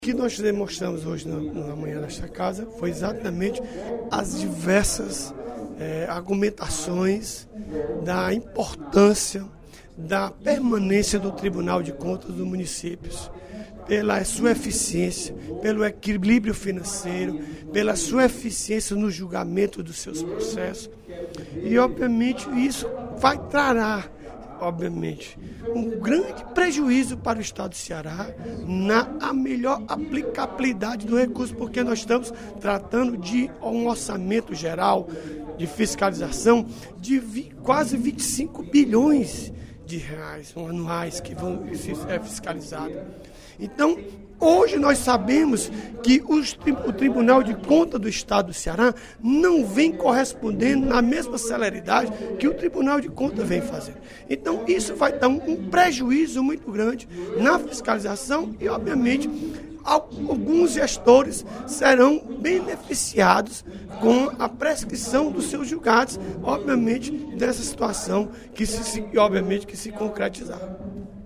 O deputado Odilon Aguiar (PMB) salientou, durante o primeiro expediente da sessão plenária desta terça-feira (13/06), a importância da permanência do Tribunal de Contas dos Municípios.